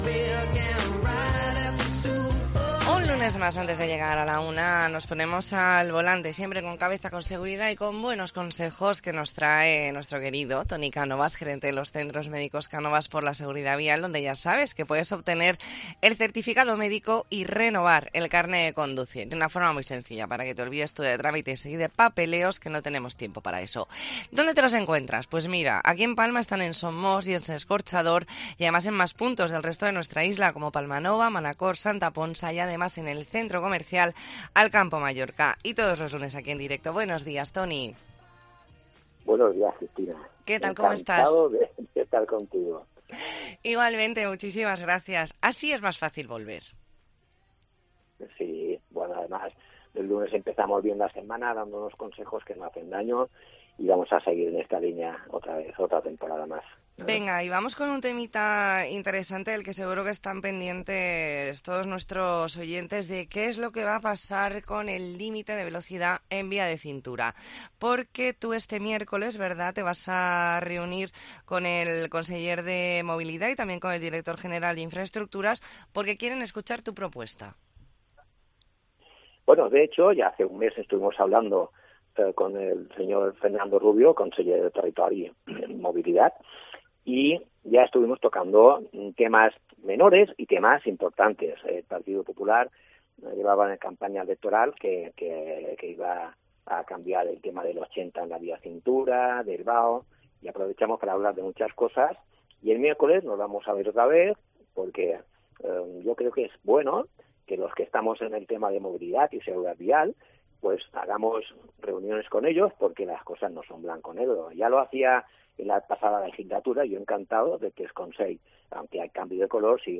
Entrevista en La Mañana en COPE Más Mallorca, lunes 25 de septiembre de 2023.